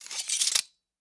Sword Sheath 1.ogg